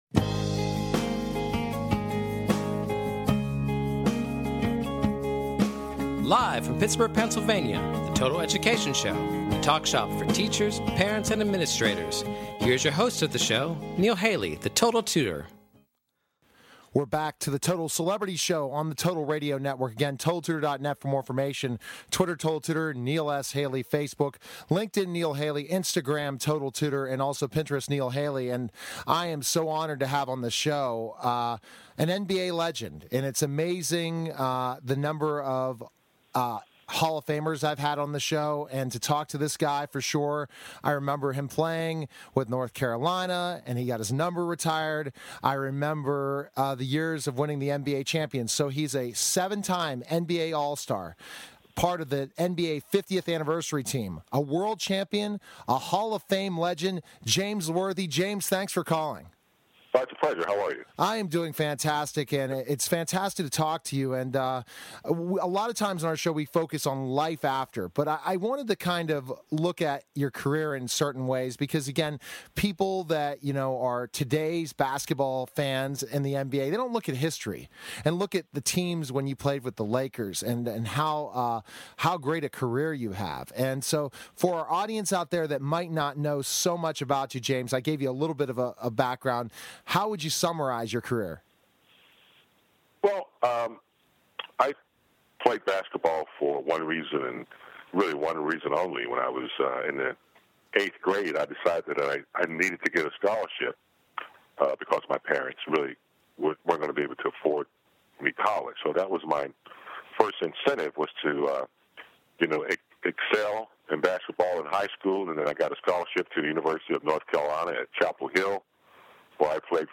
Catch weekly discussions focusing on current education news at a local and national scale.